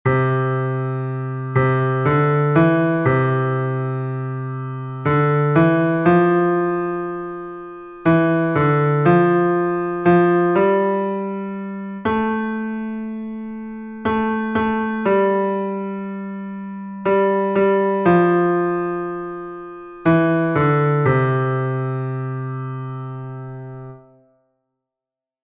Here, there are two exercises in a 6/8 time signature.
keeping the beat exercise 1